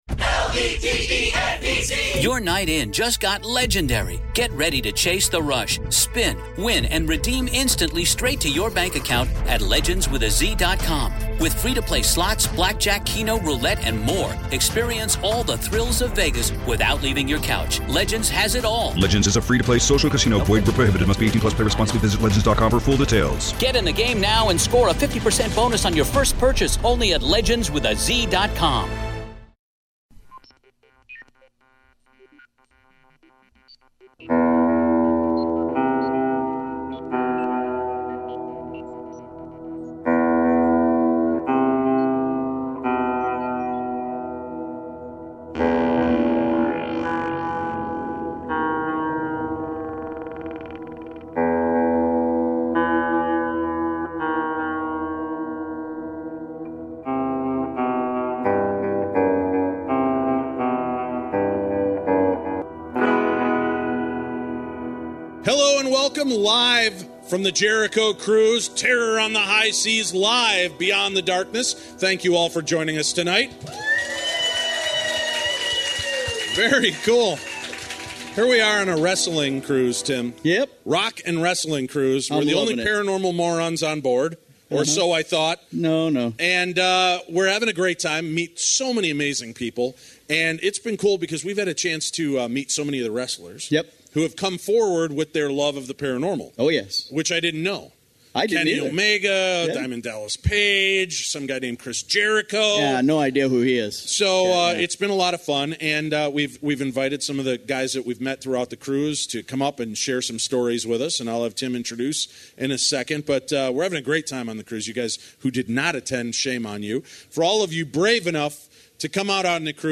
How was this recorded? Hello and welcome live from the Jericho cruise terror on the high seas live beyond the darkness.